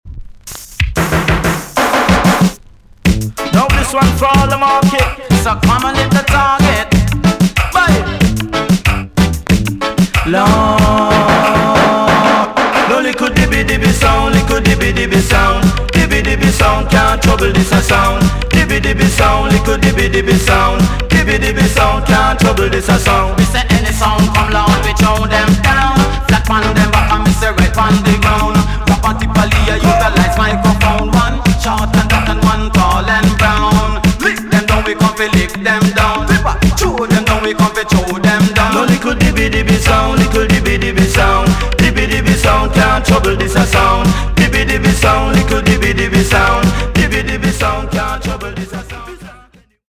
TOP >80'S 90'S DANCEHALL
EX- 音はキレイです。
WICKED COMBINATION SOUND CLASH TUNE!!